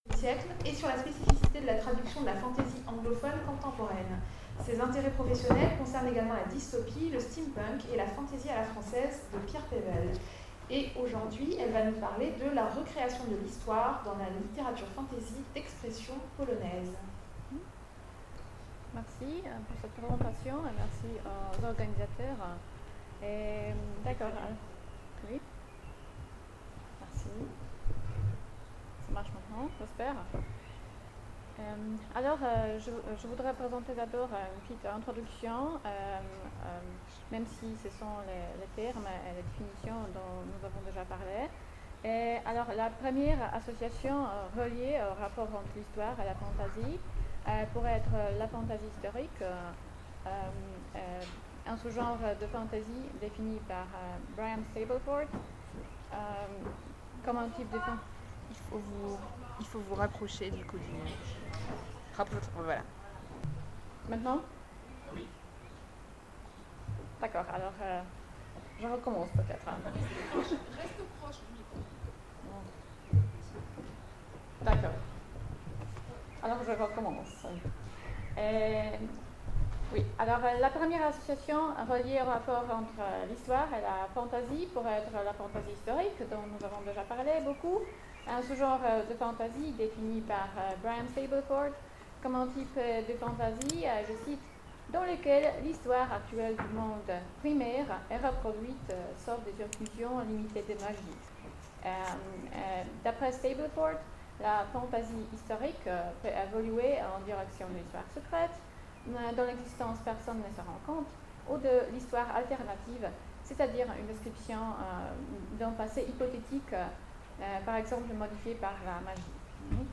Mots-clés Fantasy Conférence Partager cet article